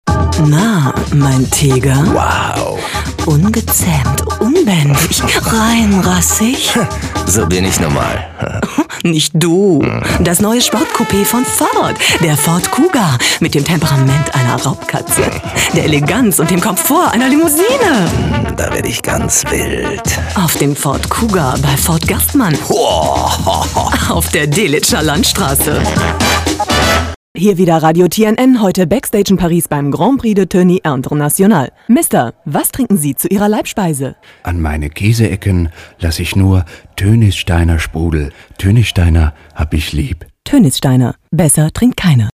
Profi-Sprecherin deutsch, Werbesprecherin.
Kein Dialekt
Sprechprobe: Industrie (Muttersprache):
female voice over artist german.